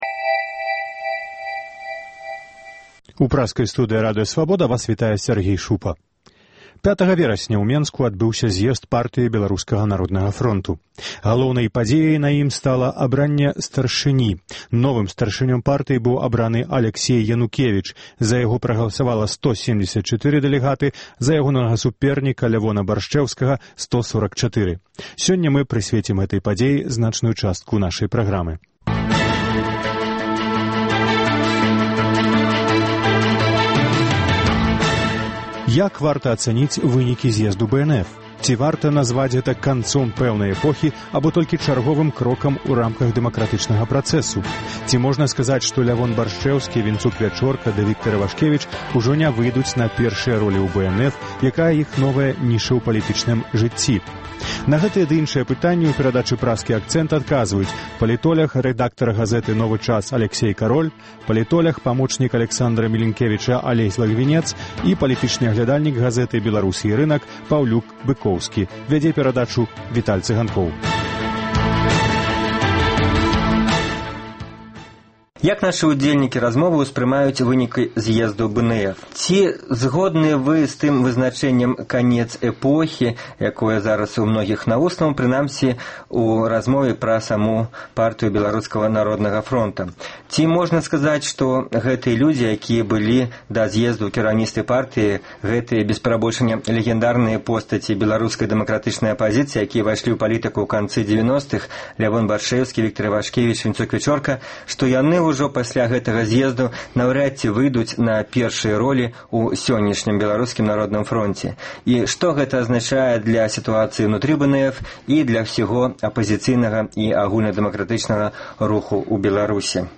Як вынікі зьезду паўплываюць на вылучэньне кандыдатаў у прэзыдэнты Беларусі? На гэтыя ды іншыя пытаньні адказваюць палітоляг